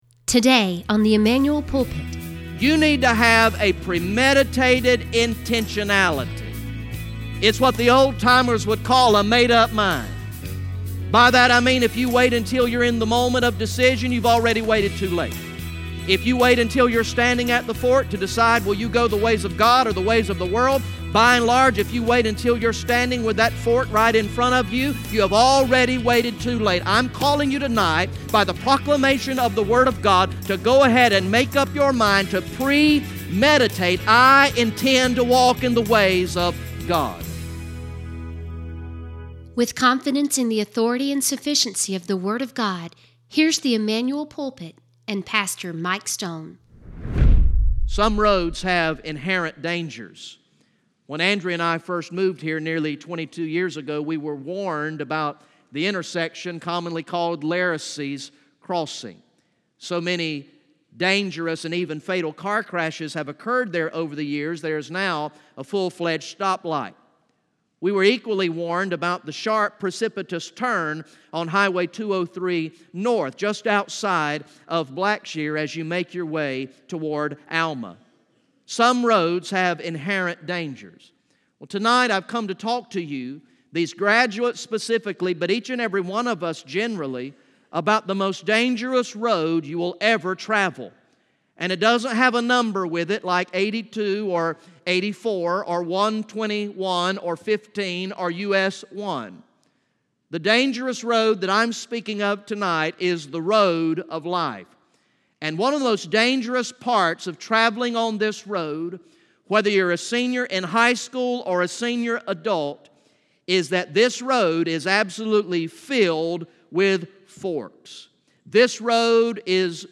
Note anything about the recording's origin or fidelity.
From the sermon series through the book of Joshua entitled "Walking in Victory" Recorded in the evening worship service on Sunday, May 6, 2018